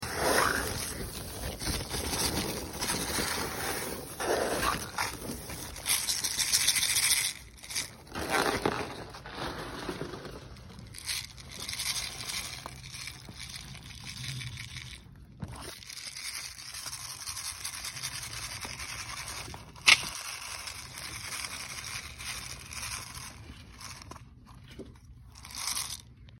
A little garden ASMR for sound effects free download